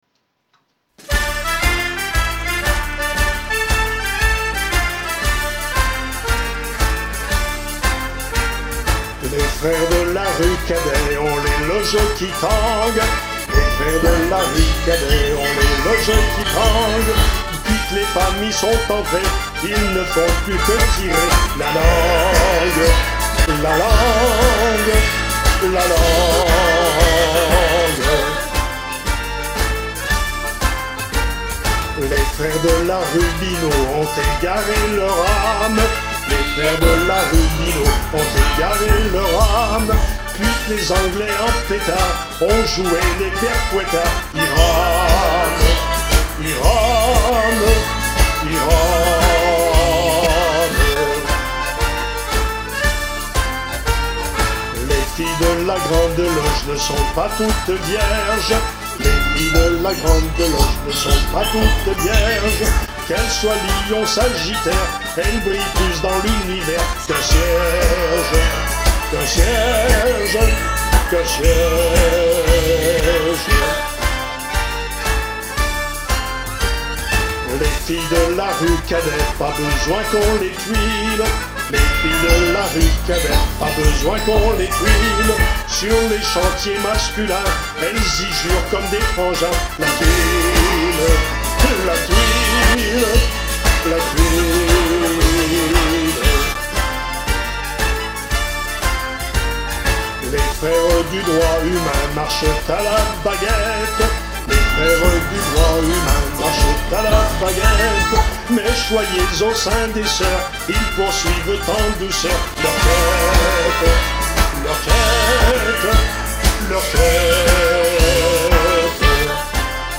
Enregistrement public festival d’Humour 2013